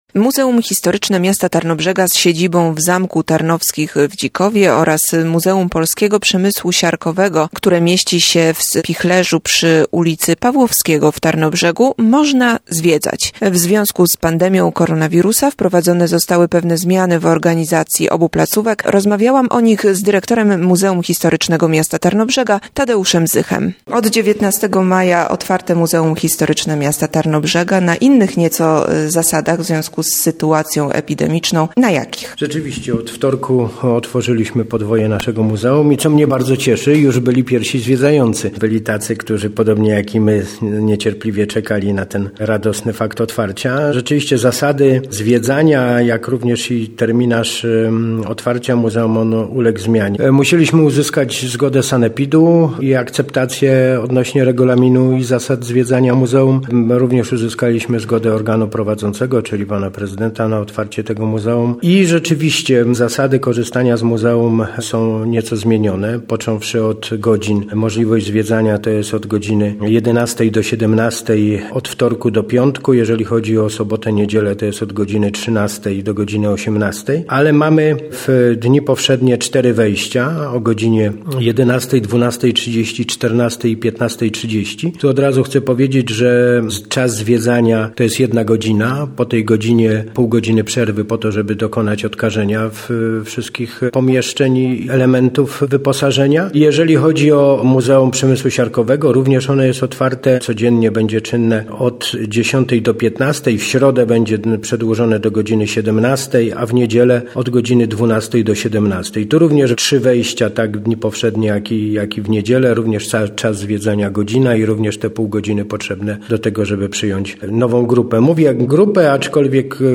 Od wtorku można zwiedzać Muzeum Historyczne Miasta Tarnobrzega. Rozmowa